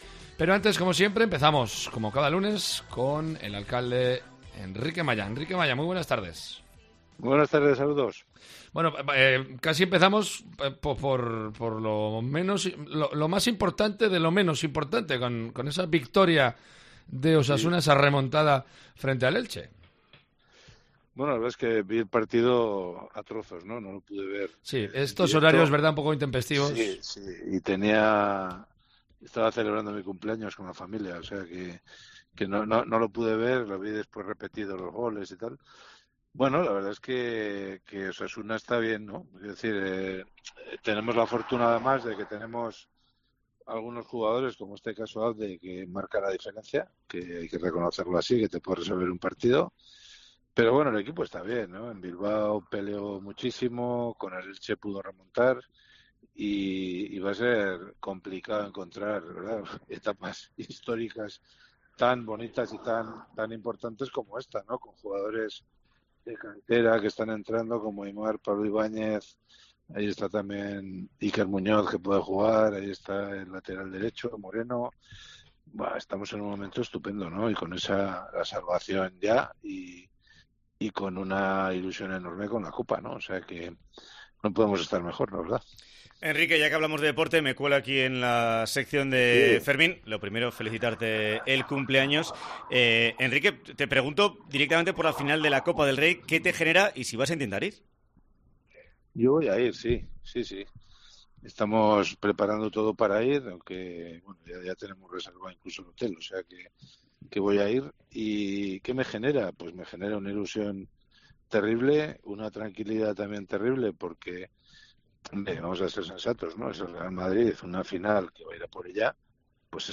Enrique Maya, alcalde de Pamplona, responde a las preguntas de los oyentes. Con Osasuna, el periodi electoral y los sanfermines como protagonistas.